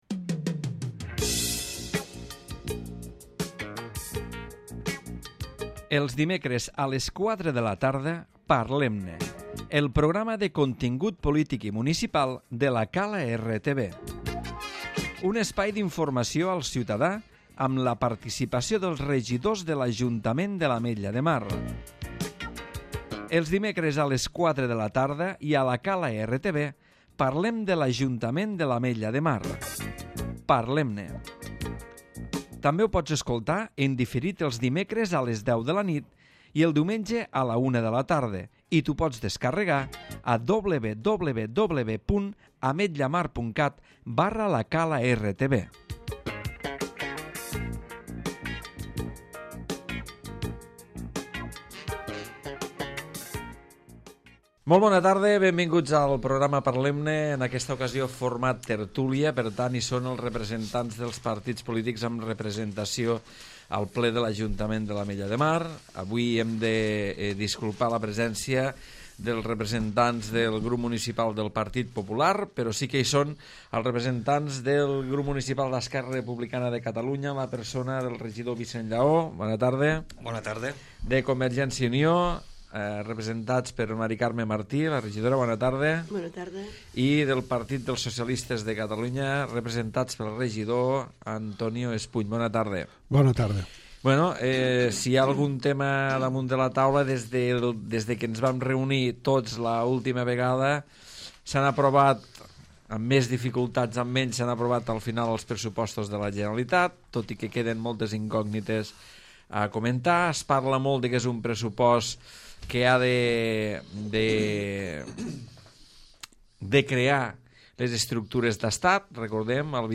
Parlem-ne Tertúlia
Vicent Llaó, M.Carme Martí i Antonio Espuny, regidors dels Grups Municipals d'ERC, CiU i PSC respectivament, opinen i analitzen l'actualitat política del país.